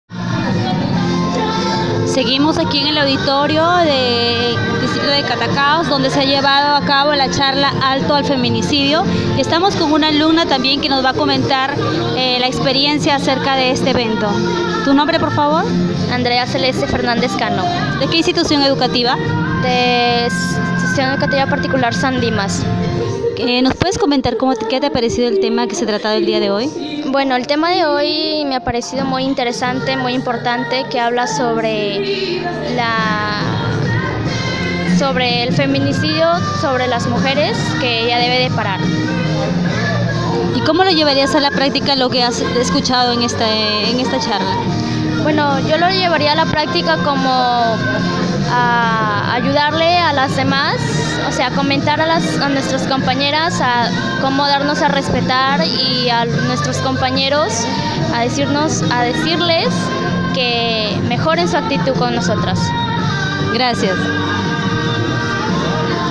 Auditorio de la municipalidad distrital de Catacaos